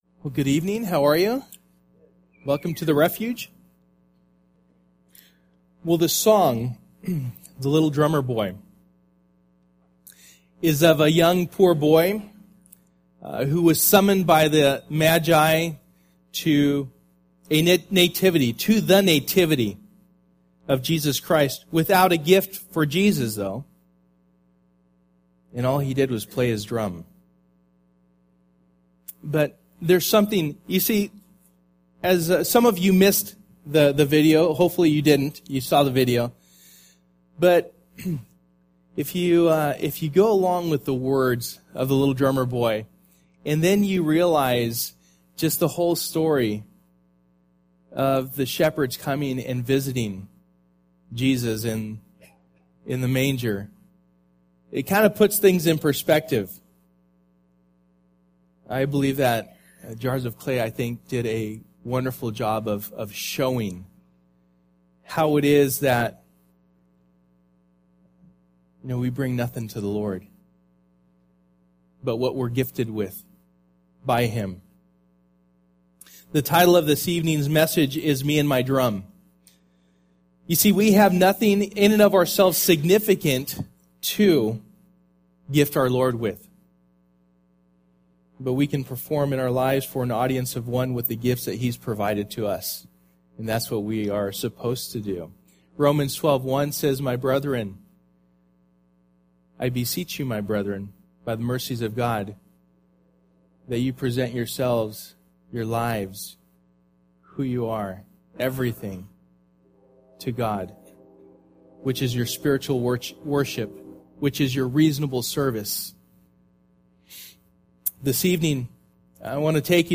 Luke 2:1-20 Service: Christmas Eve Luke 2:1-20 « Be Prepared and Believe Christmas 2011